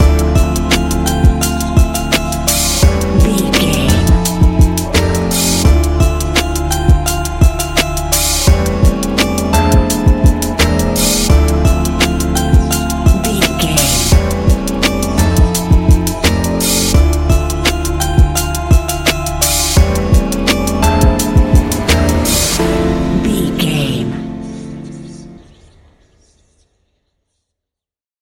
Ionian/Major
laid back
Lounge
sparse
chilled electronica
ambient
atmospheric